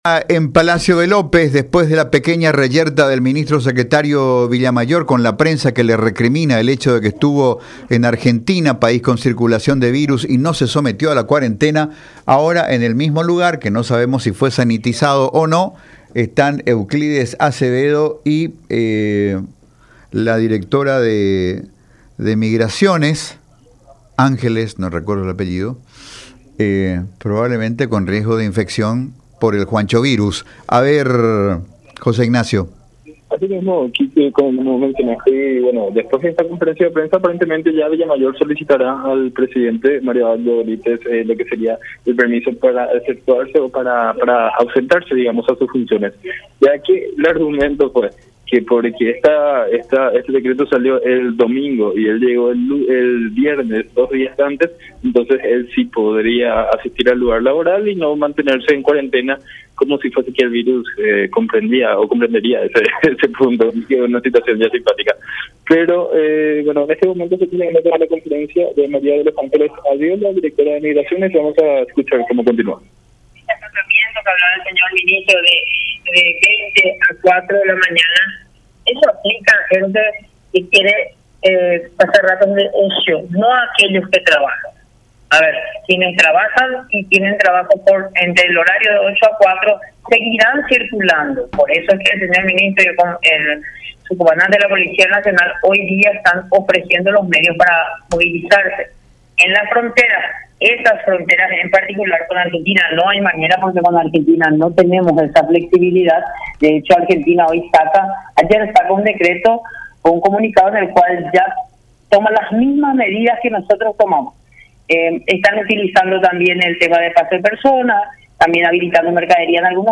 07-conferencia-euclides-acevedo.mp3